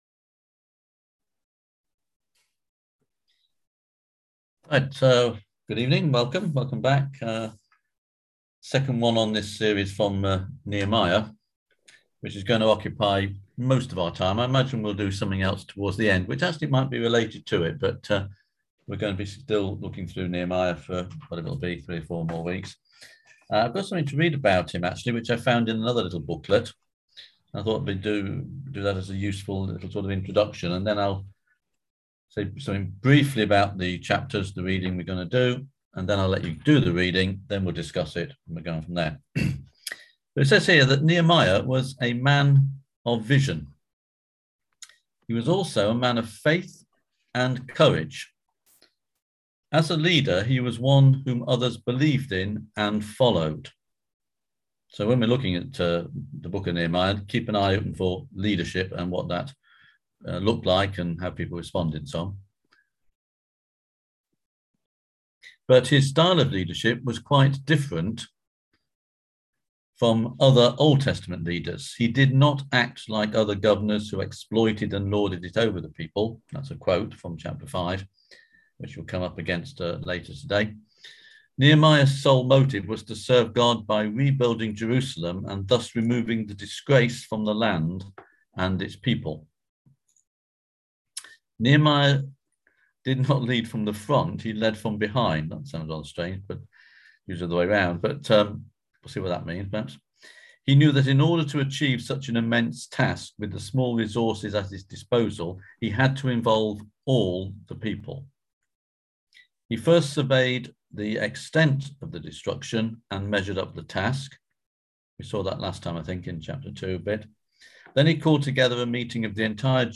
Foundations Bible school - SALTSHAKERS creativity in action
On November 11th at 7pm – 8:30pm on ZOOM